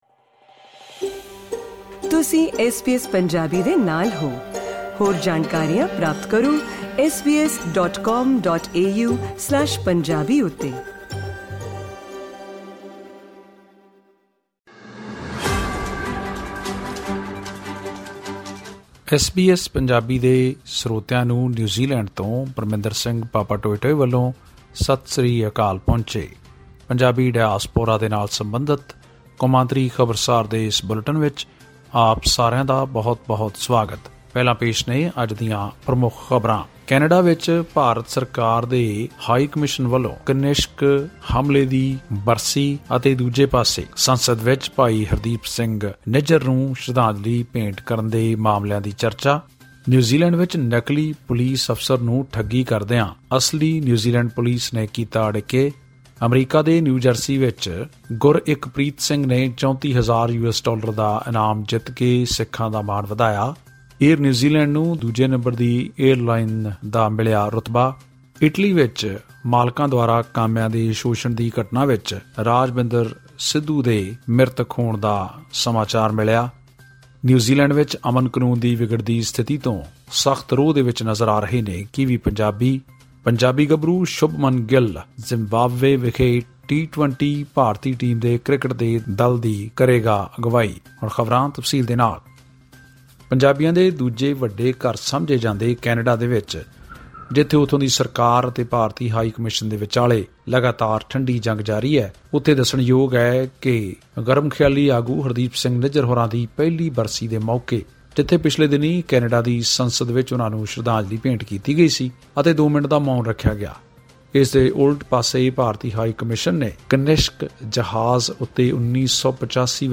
ਹੋਰ ਡਾਇਸਪੋਰਾ ਖ਼ਬਰਾਂ ਜਾਨਣ ਲਈ ਸੁਣੋ ਇਹ ਰਿਪੋਰਟ...